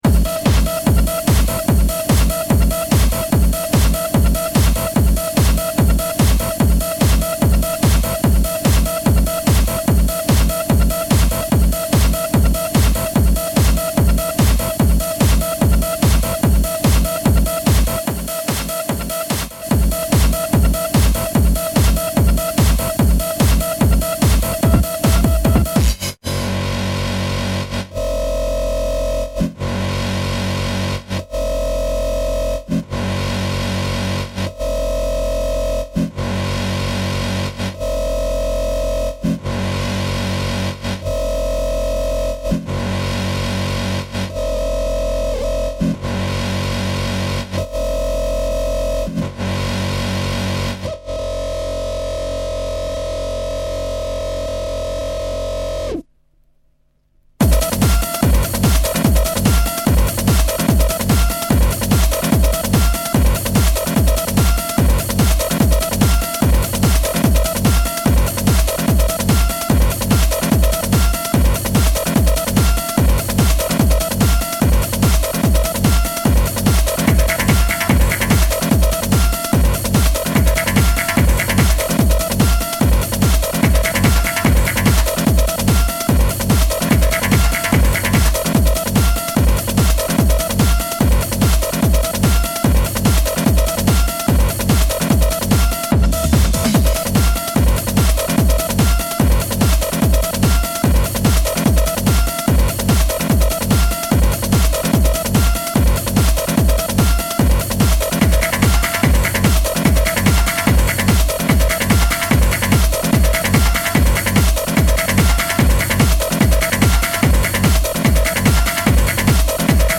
Live set at MAW Party in Rotterdam
a full on hard and relentless mix of banging techno